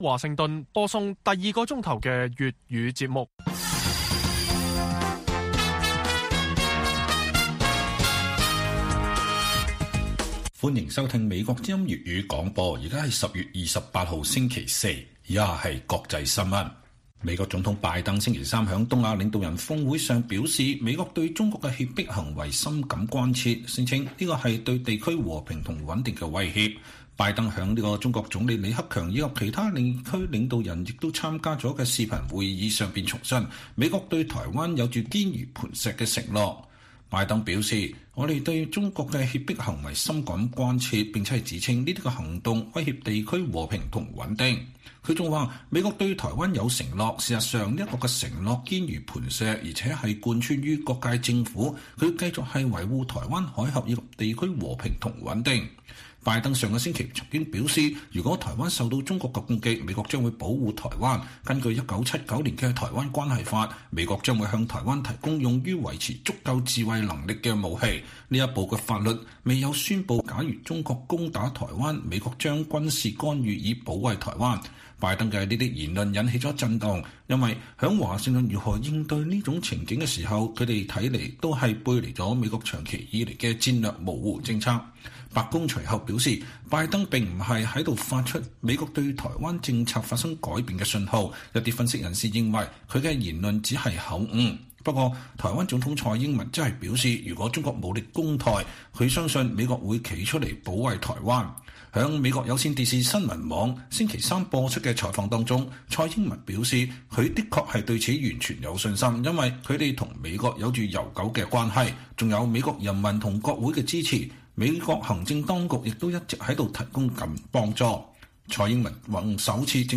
粵語新聞 晚上10-11點: 港府剔除支聯會公司註冊等同即時取締